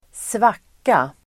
Ladda ner uttalet
Uttal: [²sv'ak:a]
svacka.mp3